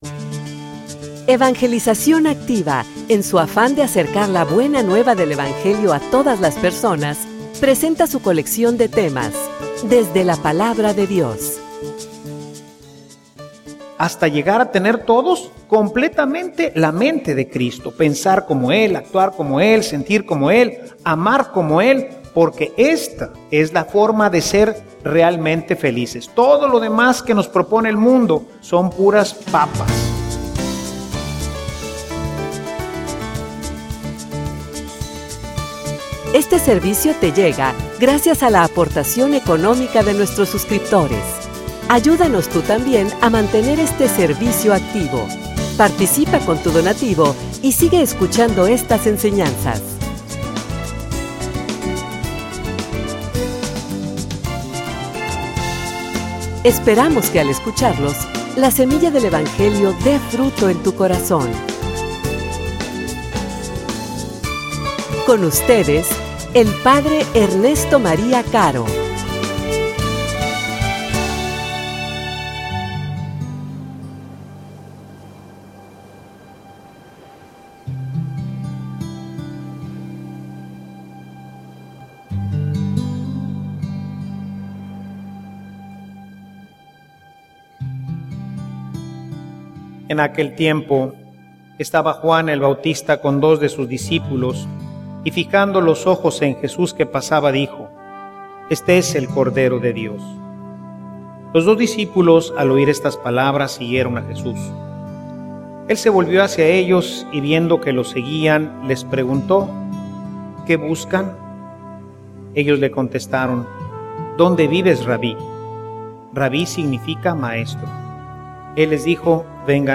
homilia_Dios_nos_llama.mp3